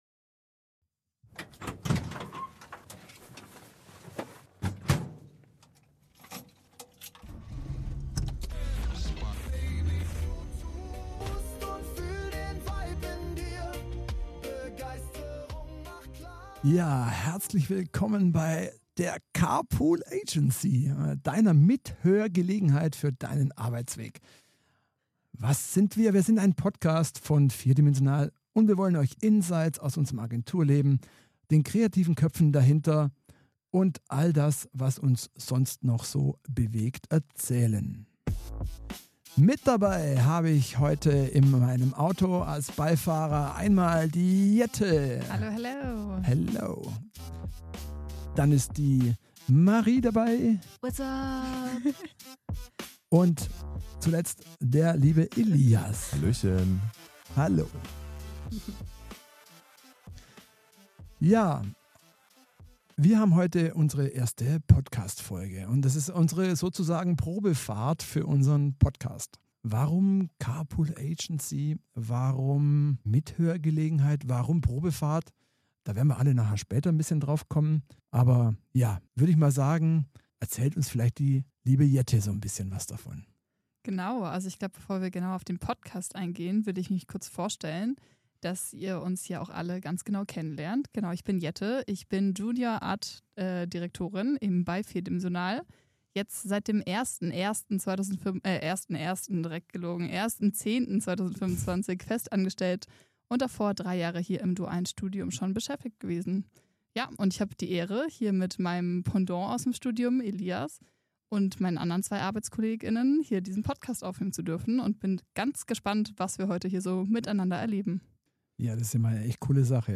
Der Motor läuft, das Mikro auch.